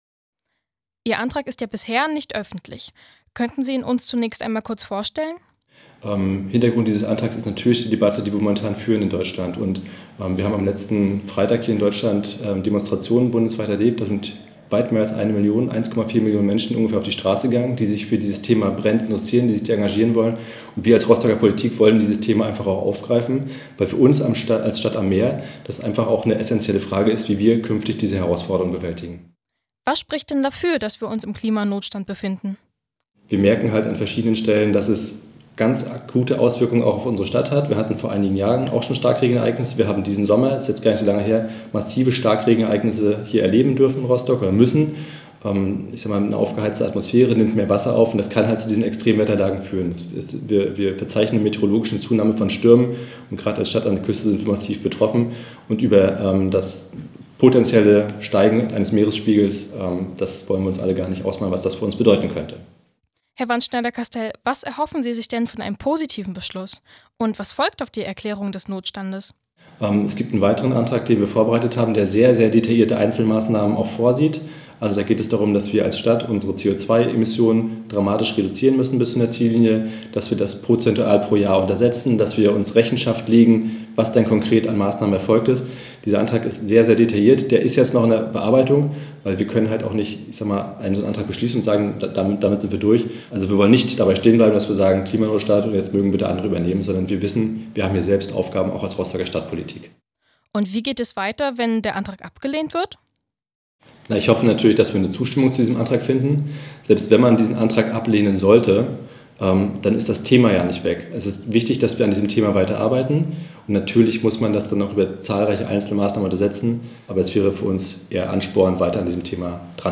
Im Gespräch mit Dr. Steffen Wandschneider-Kastell (Fraktionschef SPD)